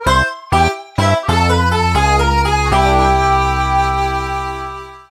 Game rip
Fair use music sample